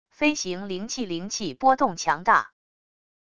飞行灵器灵气波动强大wav音频